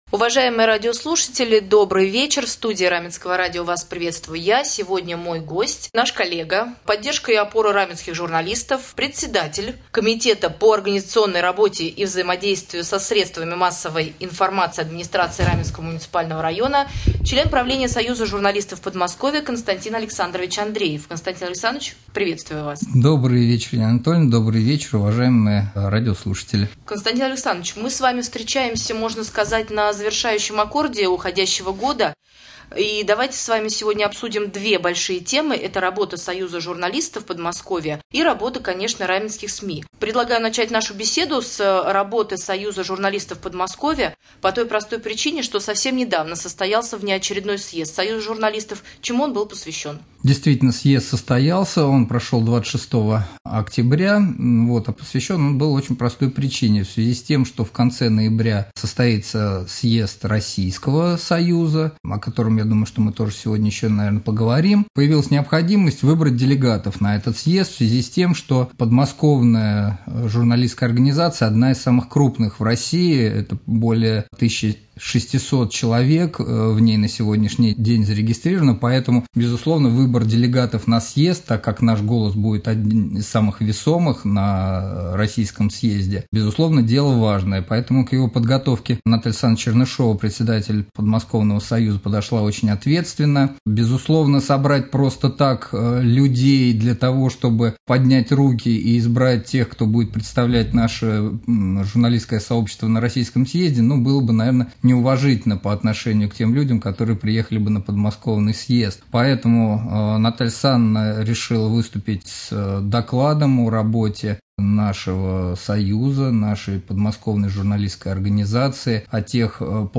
2.Рубрика «Актуальное интервью».